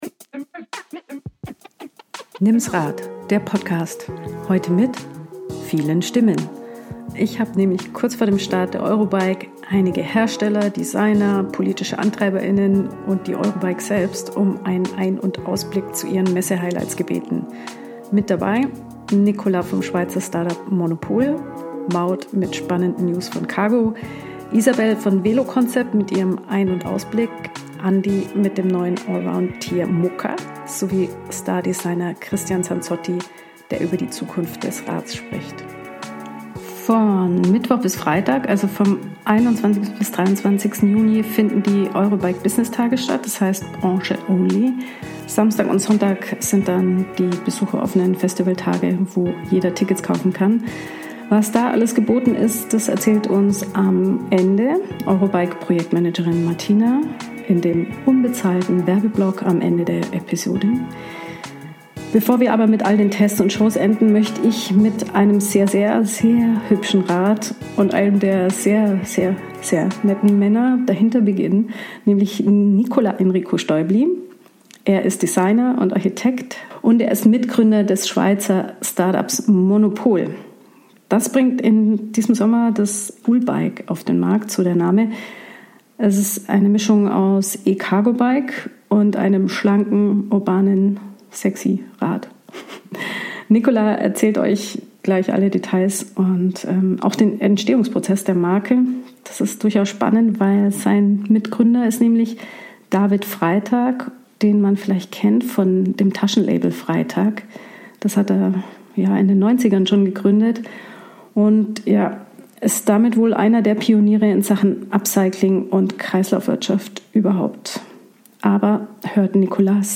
Exklusive Eurobike-Preview: Mit Stimmen von Monopole, Ca Go, Moca, Centurion uvm. ~ Nimms Rad Podcast